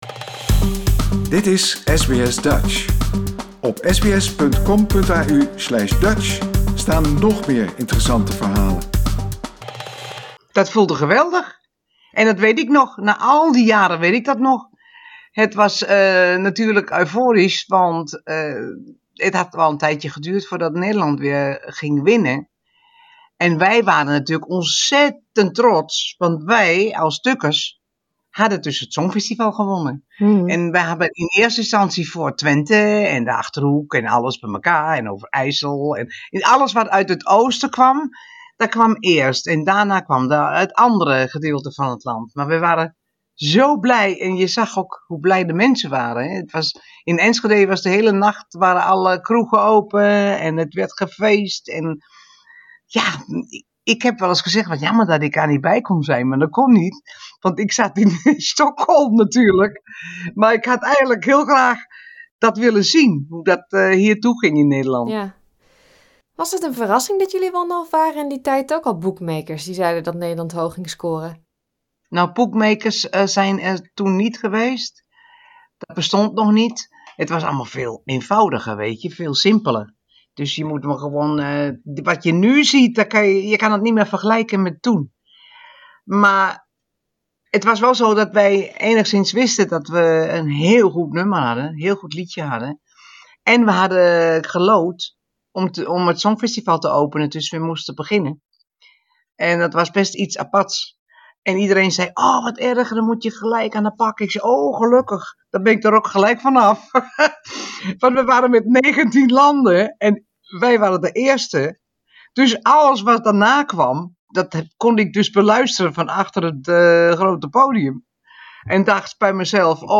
De Nederlandse band Teach-In won in 1975 het Eurovisie Songfestival met het vrolijke Ding-A-Dong. Zangeres van de band Getty Kaspers moet 46 jaar later heel hard lachen om een bepaalde zin uit het liedje. Ook vertelt ze over het moment van optreden, de gekte na de winst en de rol van de band tijdens dit jaars Songfestival in Rotterdam.